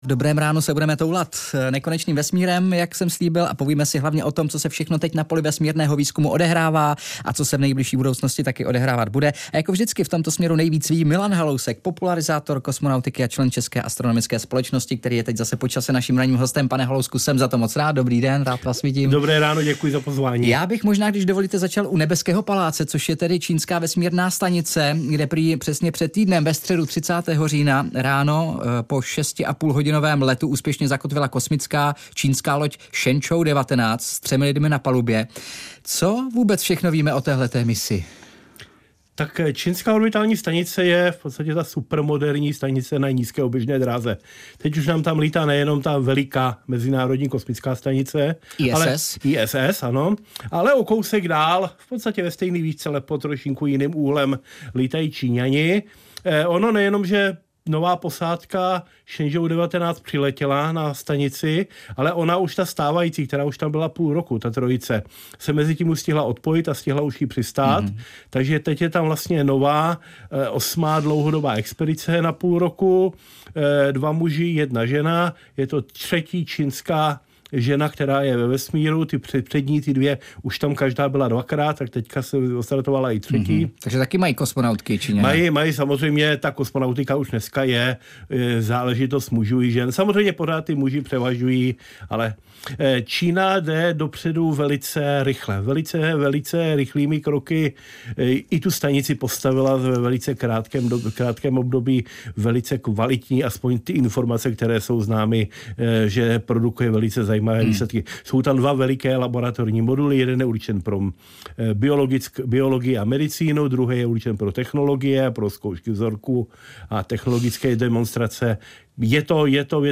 Host ve studiu: Popularizátor kosmonautiky: ISS je staré embéčko s novým motorem. Nevíte, kdy se karoserie rozpadne - 06.11.2024